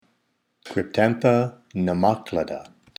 Pronunciation/Pronunciación:
Cryp-tán-tha  ne-má-cla-da